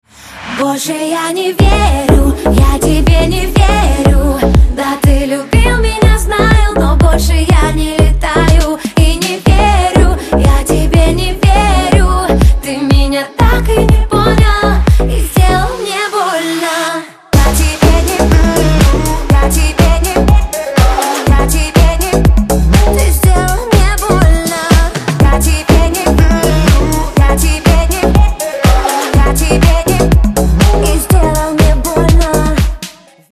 поп
женский вокал
dance